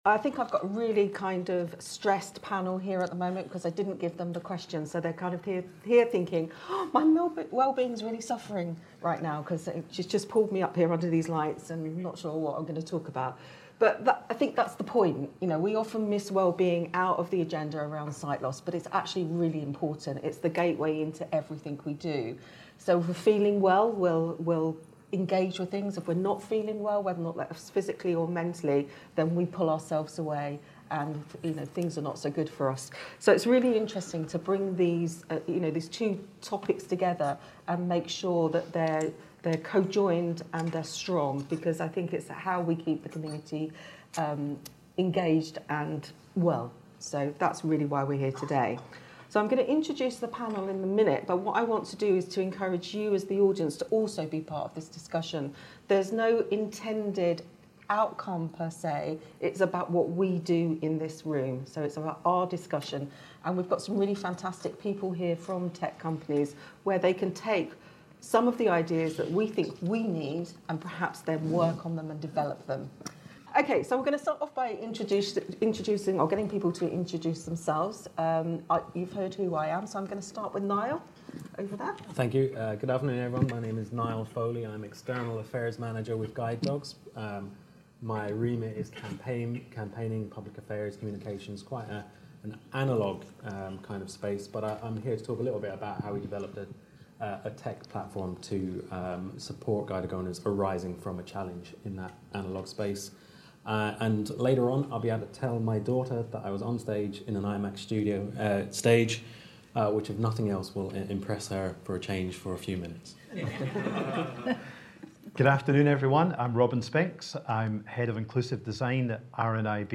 IDS Conference 2023 - Day 2 - Digital Wellbeing and Hybrid Happiness - Panel Discussion